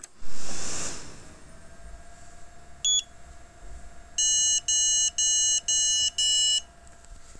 Probleme mit meinem Zotac Magnus EN72080V (Kein Bild, Piepen)
Jetzt zeigt er kein Bild mehr an und macht folgenden Piepton (Hörprobe hängt an) Leider ist das Gerät von März 2021 und die Garantie nach zwei Jahren natürlich abgelaufen.....